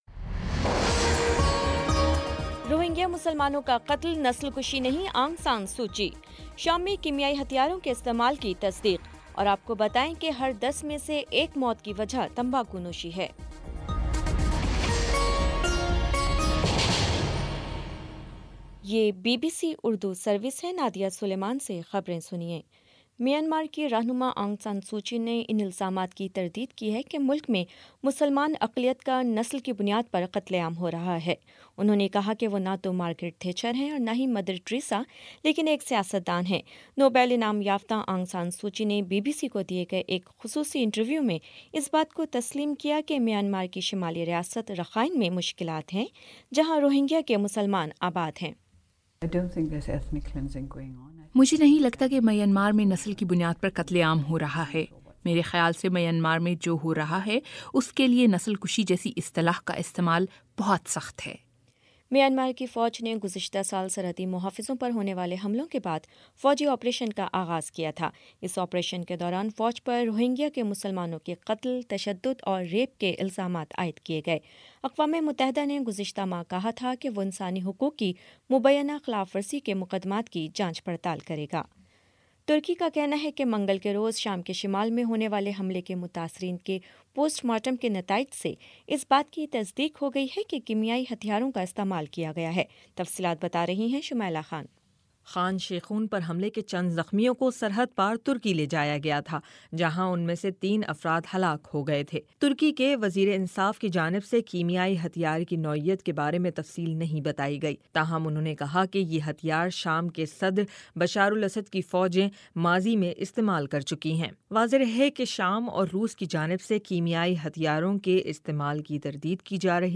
اپریل 06 : شام سات بجے کا نیوز بُلیٹن